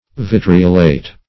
vitriolate - definition of vitriolate - synonyms, pronunciation, spelling from Free Dictionary
Vitriolate \Vit"ri*o*late\, v. t. [imp. & p. p. Vitriolated;